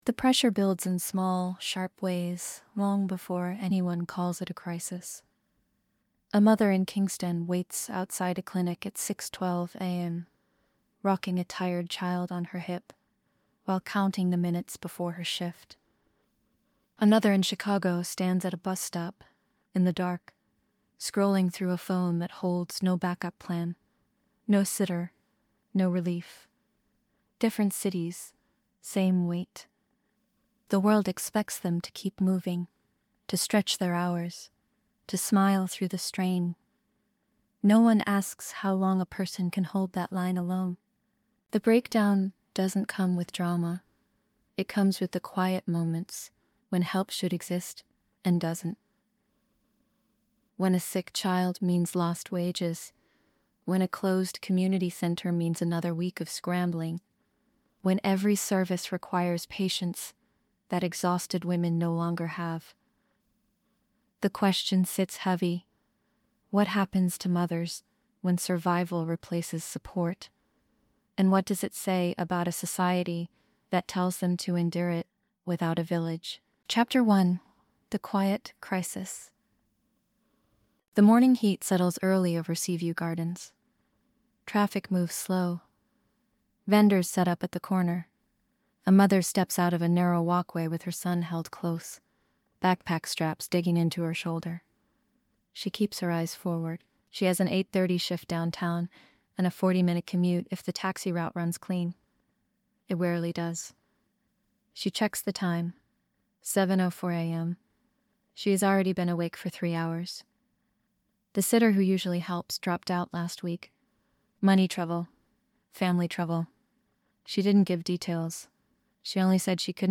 This investigative three-chapter series follows two mothers, one in Kingston and one in Chicago, as they navigate the daily pressure of raising children without stable support systems. Through lived scenes, interviews, and documented evidence, the story exposes how isolation, unstable jobs, transportation gaps, and underfunded programs quietly grind women down.